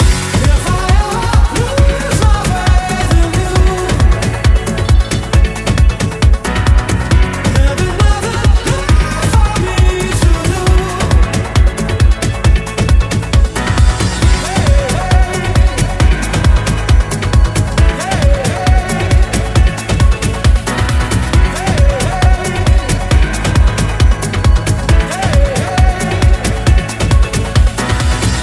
Genere: pop,dance,afrobeat,house,latin,edm,remixhit